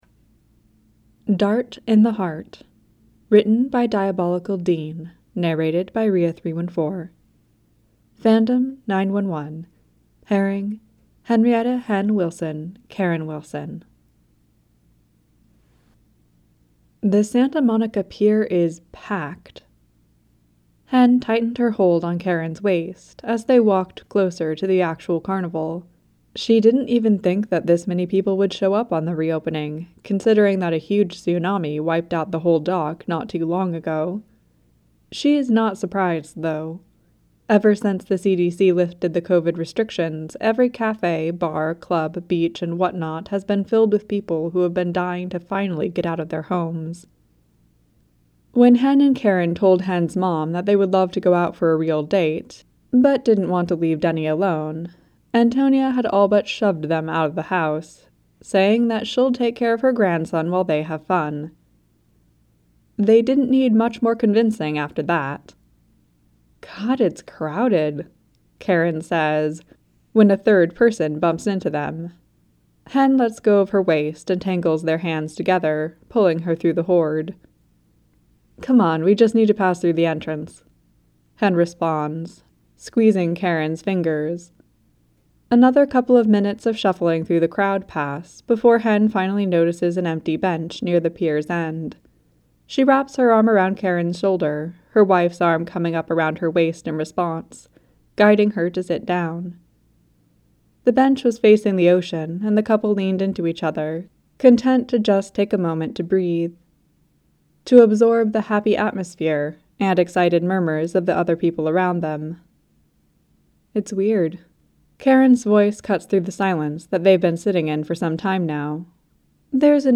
with music and sfx: download mp3: here (r-click or press, and 'save link') [17 MB, 00:14:55]